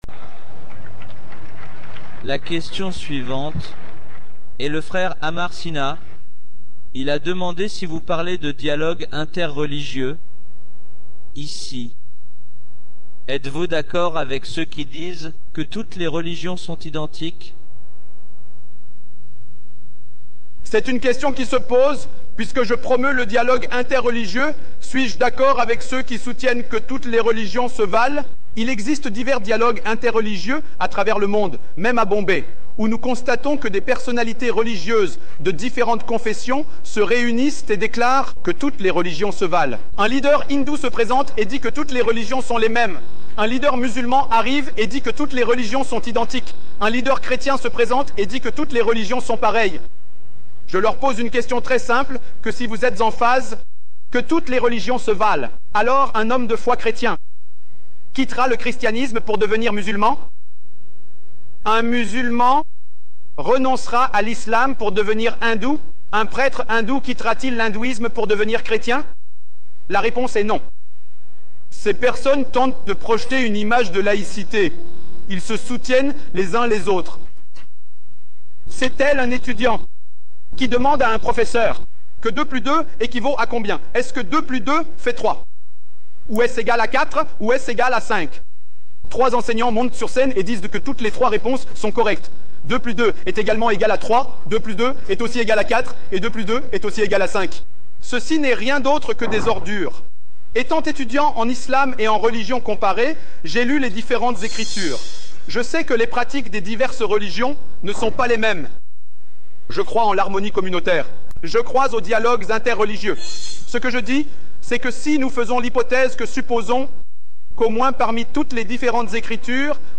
Description: Dans cette vidéo, le Dr Zakir Naik explique pourquoi il est injuste de dire que toutes les religions sont les mêmes.